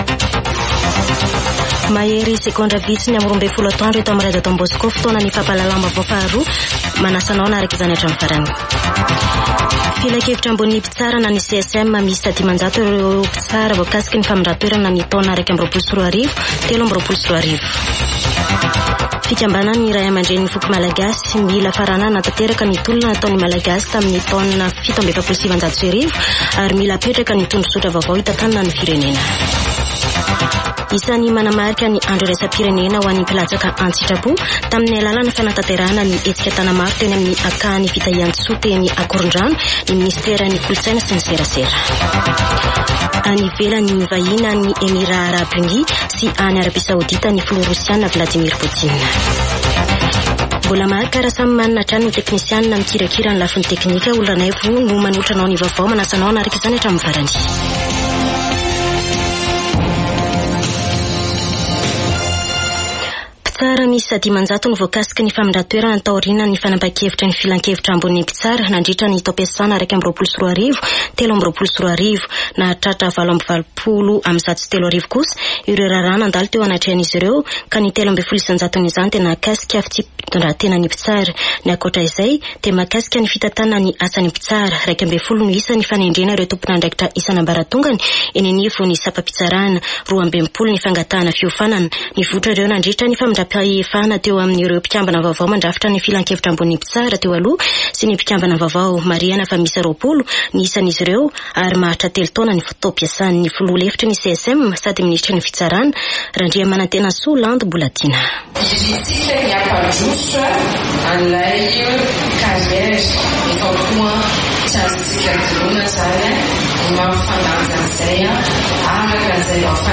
[Vaovao antoandro] Alarobia 6 desambra 2023